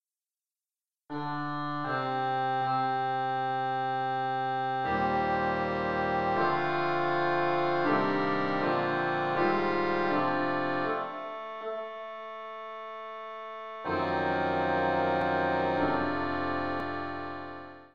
Key written in: F Major
How many parts: 4
All Parts mix:
Learning tracks sung by
a reed organ